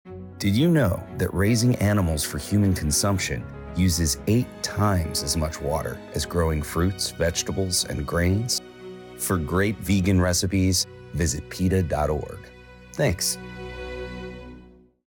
environment_15_second_radio_psa_updated_version.mp3